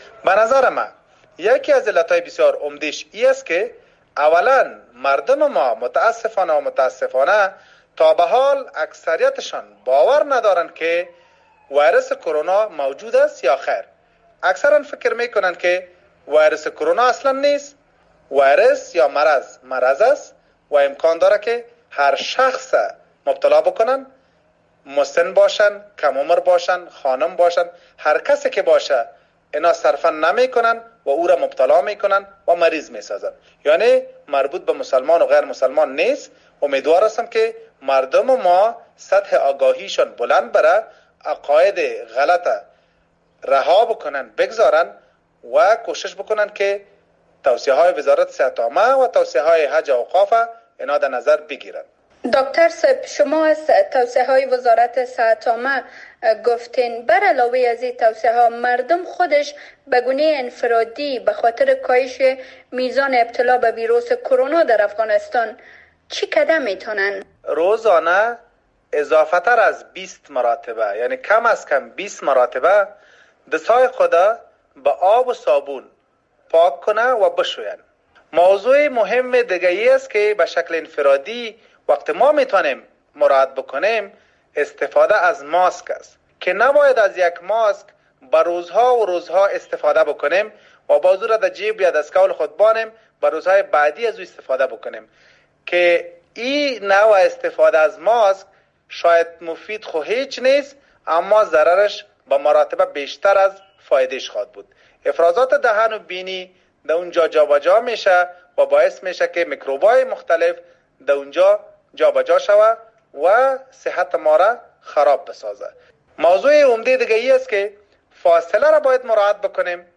از راه دور مصاحبه کرده